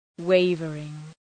Shkrimi fonetik {‘weıvərıŋ}